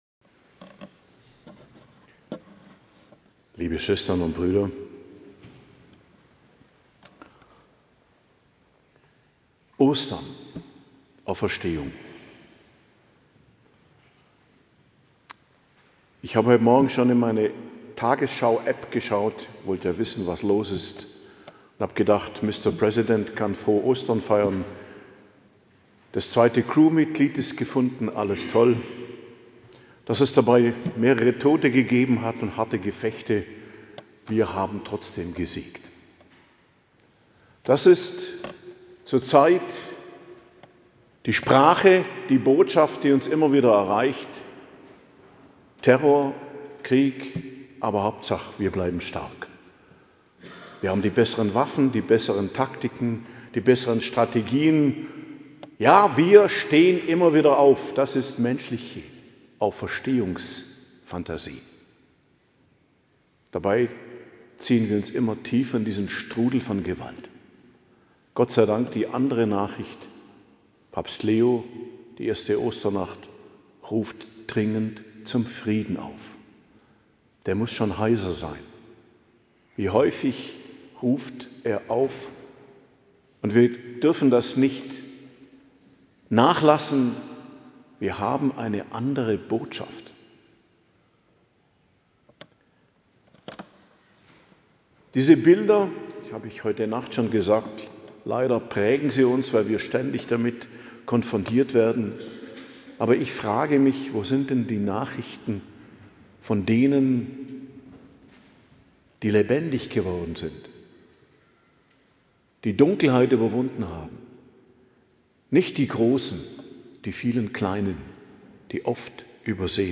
Predigt zum Ostersonntag, 5.04.2026 ~ Geistliches Zentrum Kloster Heiligkreuztal Podcast